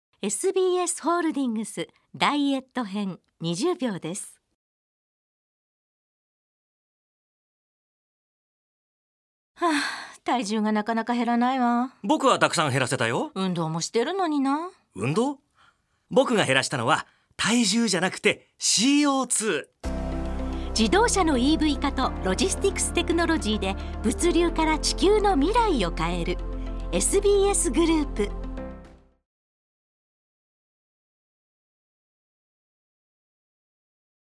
現在「ニッポン放送ショウアップナイター」で放送中のラジオCMです。
SBSグループ ラジオCM「ダイエット」篇 20秒 [mp3]